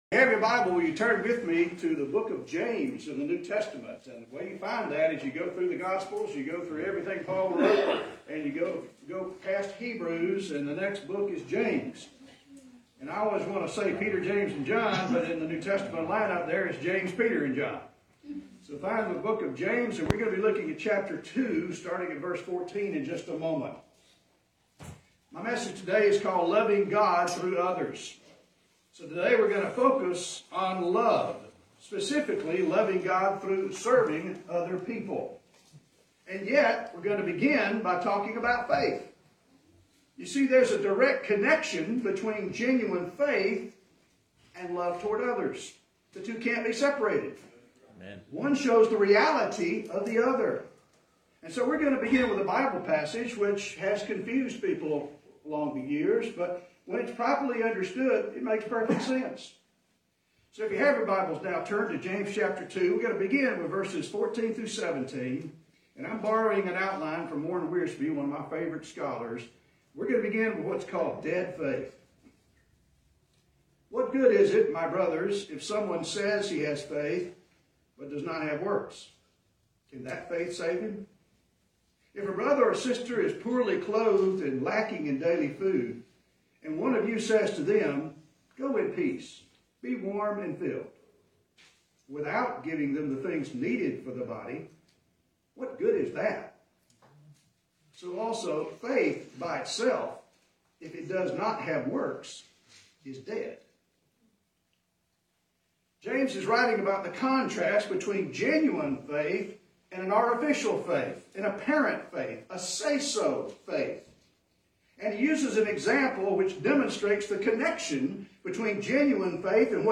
Message By Warren W. Wiersbe